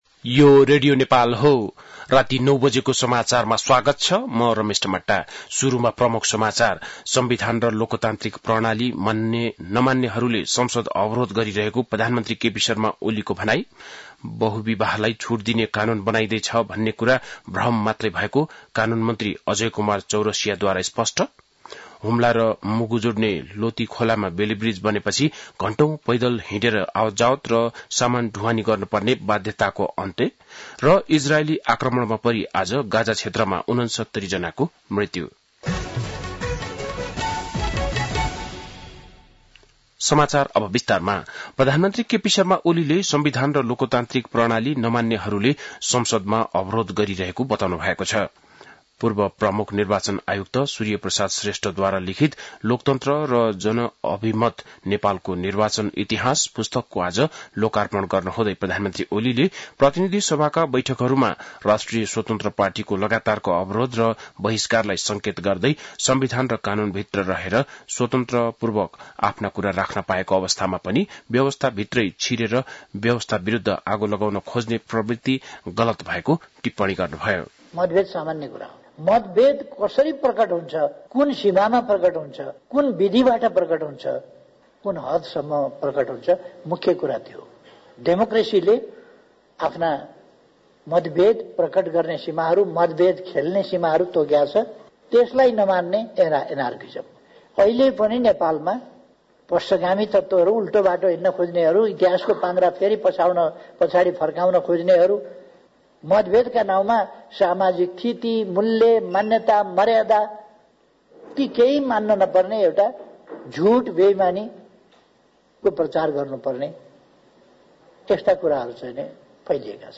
बेलुकी ९ बजेको नेपाली समाचार : १८ साउन , २०८२
9-pm-nepali-news-.mp3